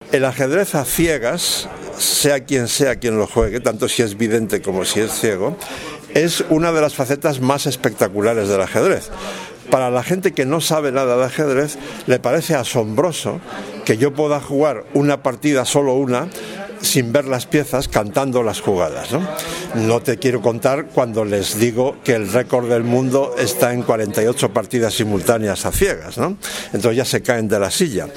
El periodista español especializado en ajedrez Leontxo García, vinculado a El País desde 1985, ofreció, el pasado 22 de septiembre, una charla-conferencia en el salón de actos de la Delegación Territorial de la ONCE en Madrid bajo el título “El ajedrez ayuda a pensar”